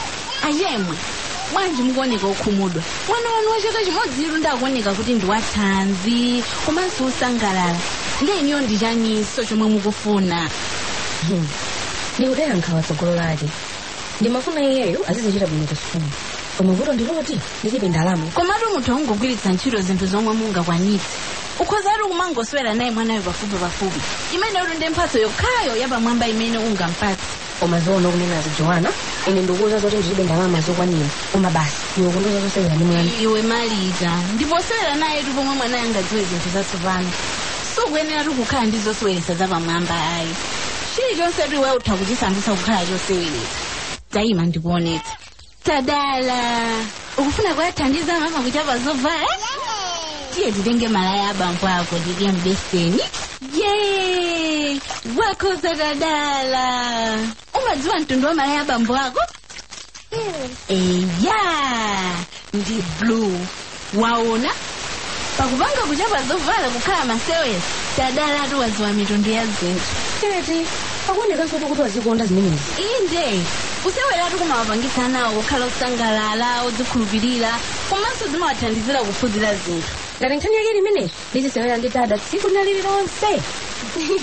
Station: MBC R1